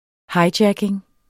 Udtale [ ˈhɑjˌdjageŋ ]